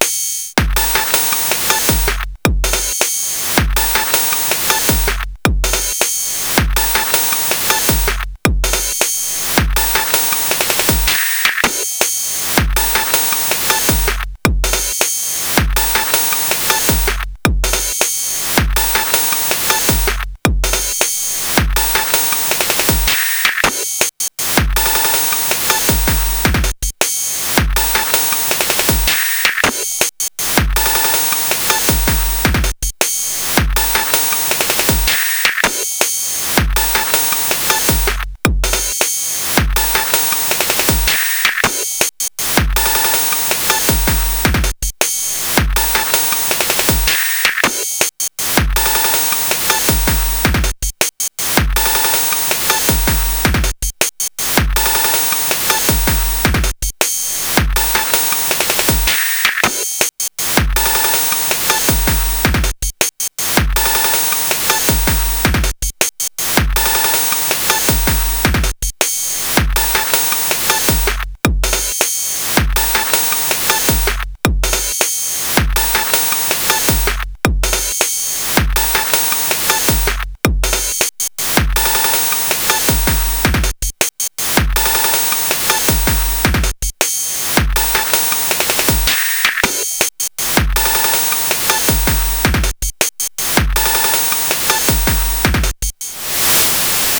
Made with a PO-12 and an SP-404.80BPM Licensed under a Creative Commons Attribution 4.0 International License.